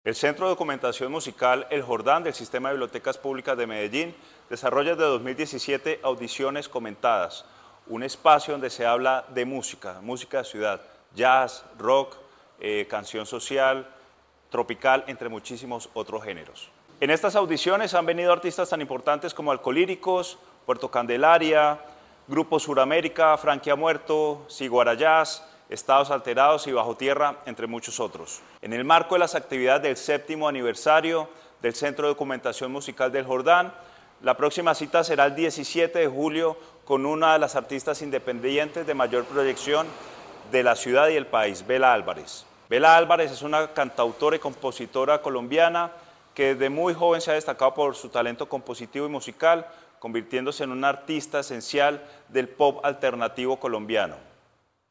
Palabras de Andrés Sarmiento, subsecretario de Bibliotecas, Lectura y Patrimonio